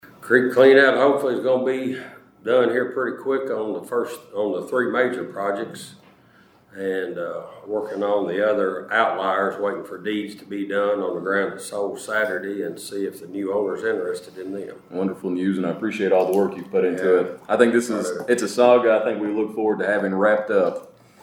Caldwell County Fiscal Court heard updates on proposed and ongoing community projects during a brief meeting on Tuesday morning.
During reports, District 2 Magistrate Jeff Boone shared an update on the progress of Emergency Watershed Protection creek cleanout projects.